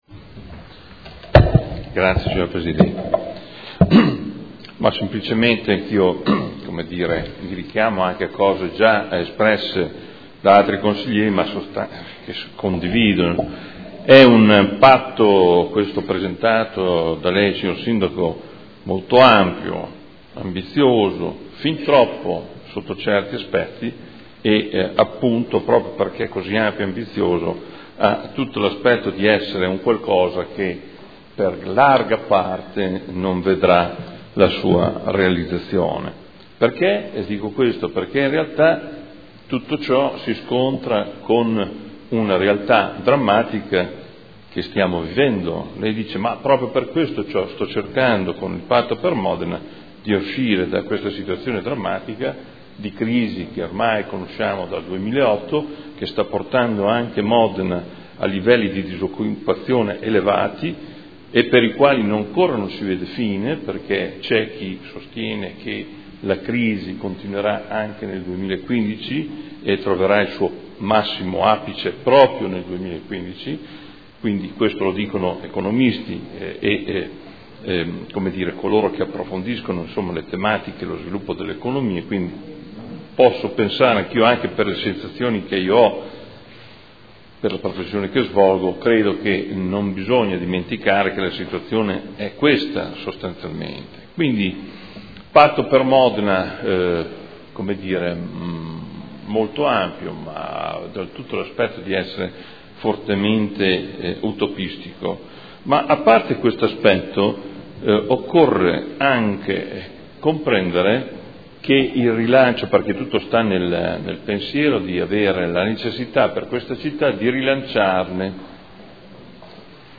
Seduta del 20/11/2014. Dibattito su Ordini del Giorno e Mozione aventi per oggetto "Patto per Modena"